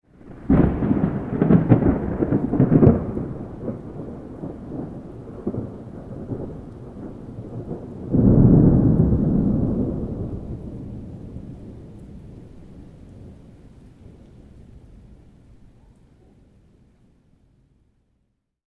thunder_3.ogg